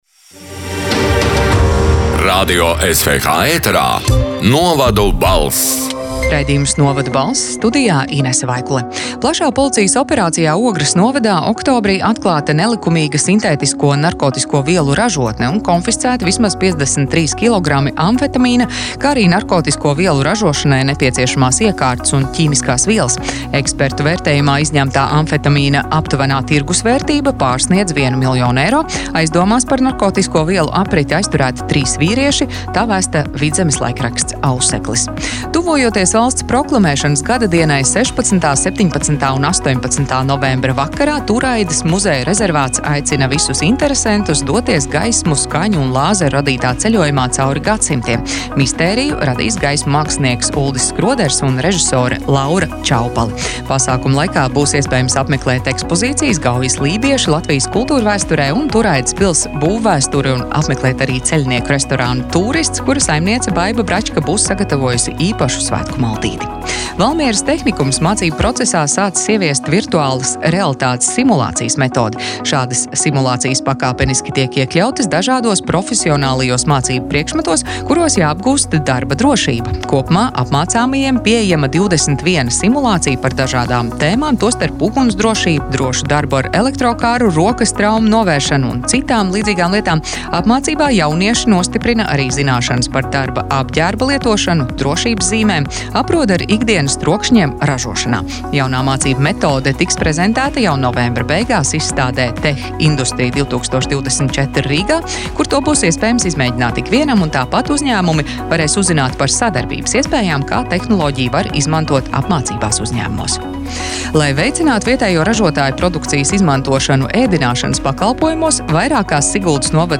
Radio SWH ēterā divreiz nedēļā izskan ziņu raidījums “Novadu balss”, kurā iekļautas Latvijas reģionālo mediju sagatavotās ziņas.
“Novadu balss” 6. novembra ziņu raidījuma ieraksts: